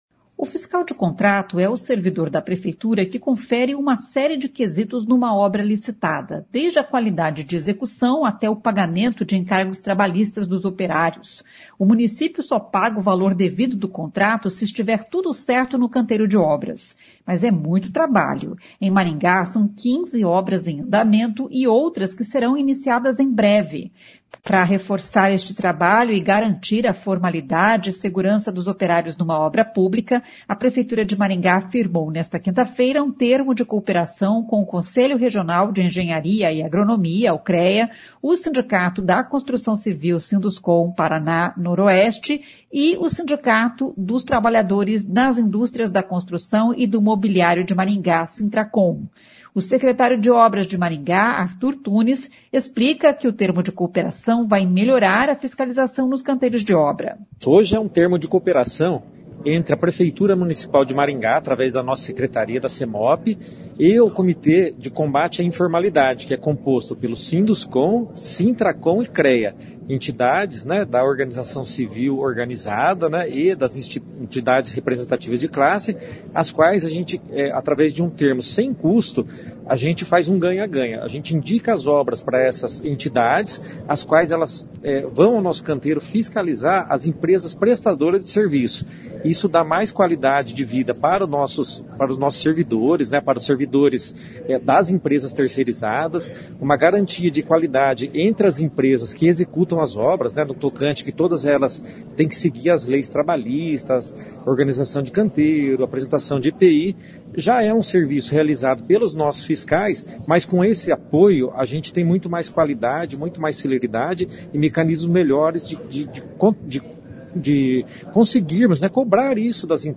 O secretário de Obras de Maringá, Artur Tunes, explica que o termo de cooperação vai melhorar a fiscalização nos canteiros de obra.
O prefeito Silvio Barros diz que a parceria se reflete em qualidade e eficiência.